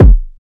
Butterfly Effect ( Kick )(1).wav